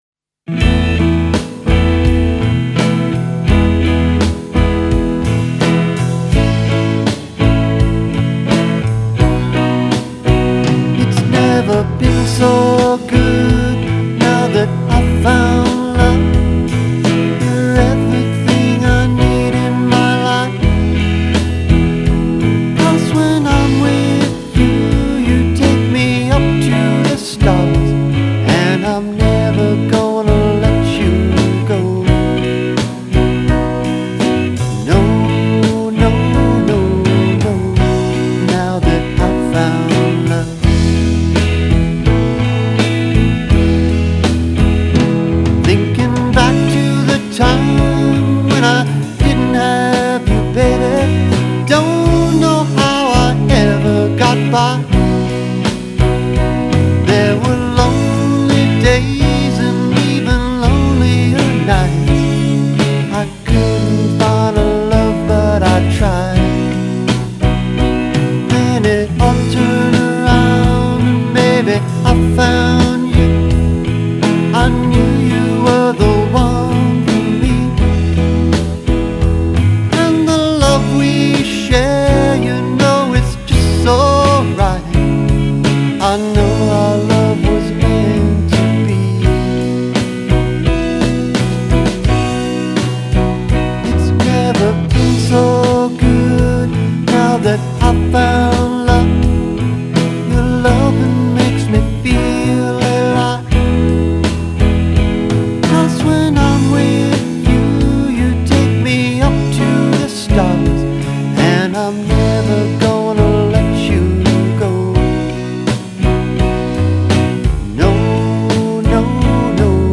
Here's an original ballad that I recently had mastered called Now That I Found Love. Me - Guitars, piano, vocals
Guitars - Kramer Pacer Imperial/Roland GP8/ Marshall JCM900 / SM57
Vocal Mic - Rode NTK
He added eq/compression/fx in various amounts to the tracks which improved the final mix quite a bit ;-) .